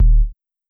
808 (Lifting You).wav